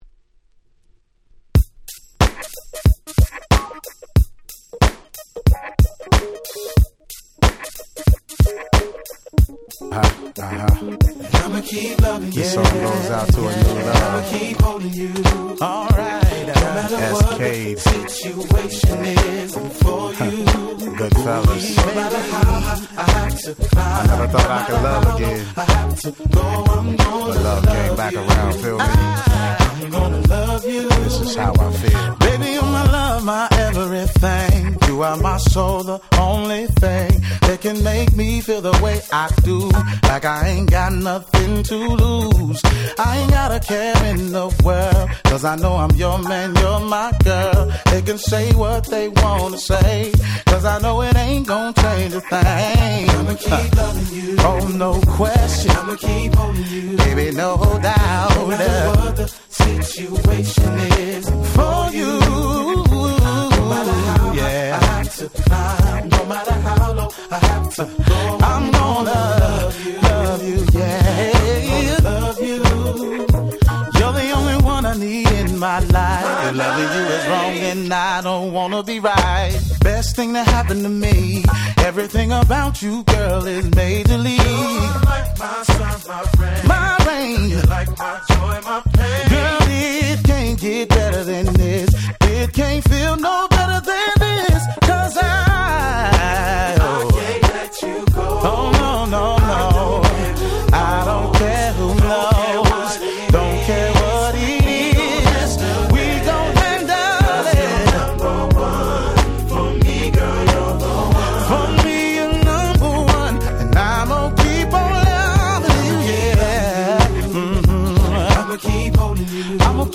02' Nice R&B / Hip Hop Soul !!
いぶし銀ですが大変良質なR&B !!
SmoothでGroovy、めちゃ最高です！！